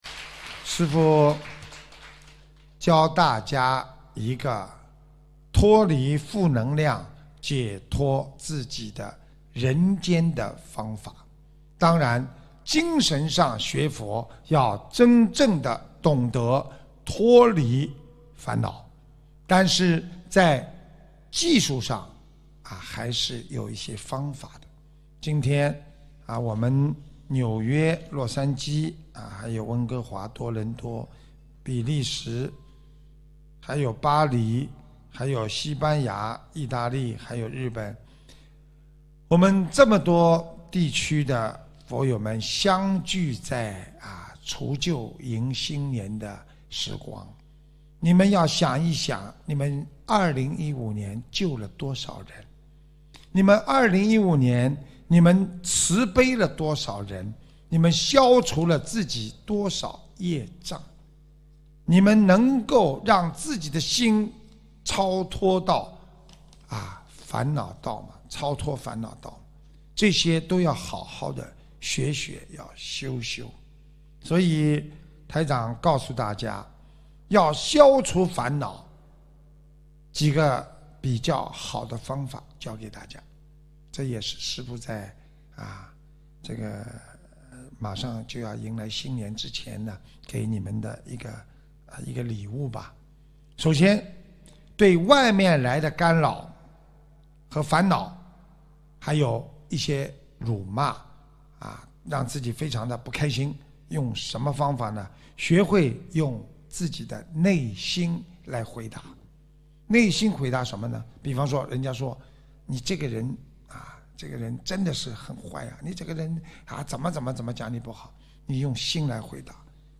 1师父教大家一个脱离负能量、解脱自己的人间的方法 2016年01月 海外弟子新年开示